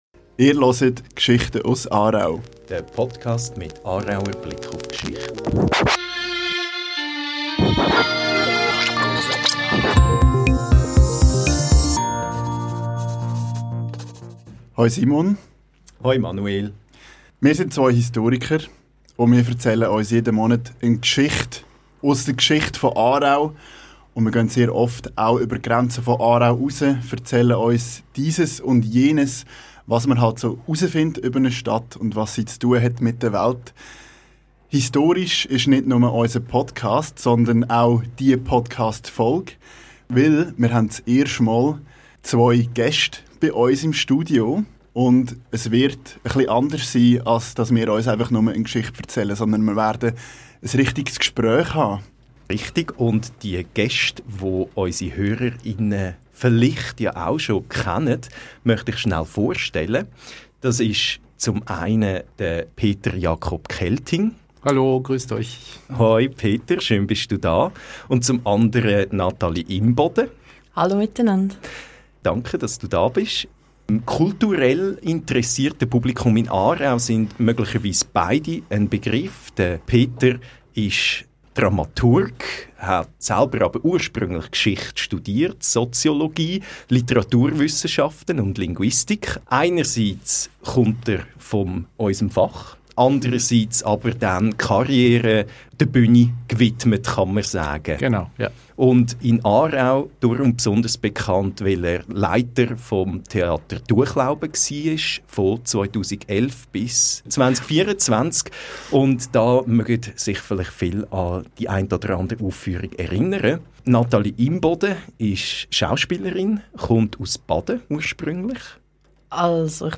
Mit Auszügen aus den Originaldokumenten, gelesen von Schauspielerin